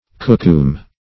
kookoom - definition of kookoom - synonyms, pronunciation, spelling from Free Dictionary Search Result for " kookoom" : The Collaborative International Dictionary of English v.0.48: Kookoom \Koo"koom\, n. (Zool.) The oryx or gemsbok.